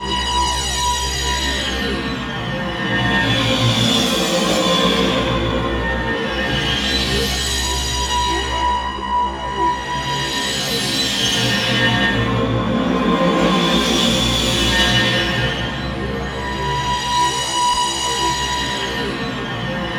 Index of /90_sSampleCDs/Spectrasonic Distorted Reality 2/Partition G/01 DRONES 1